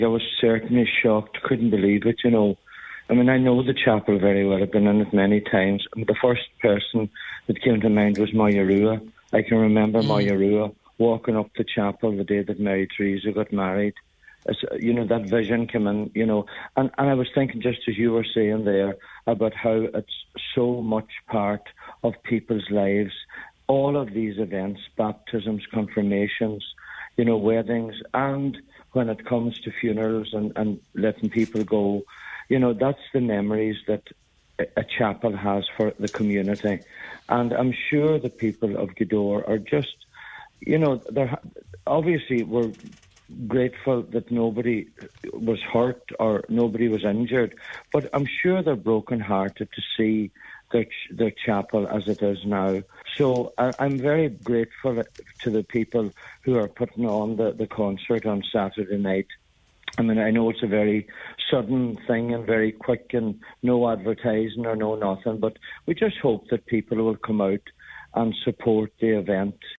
Speaking on today’s Nine til Noon Show, Daniel O’Donnell says the Church was at the heart of the local community who have been left devastated: